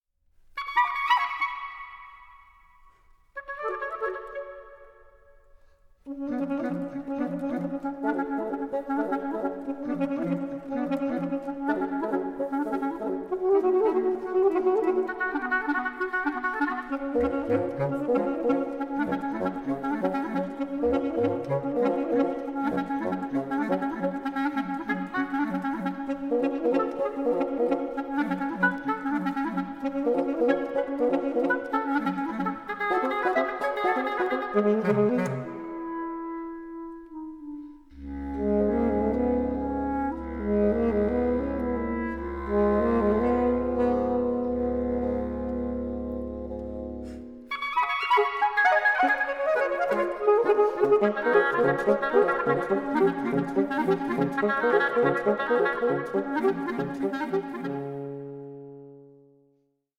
reed players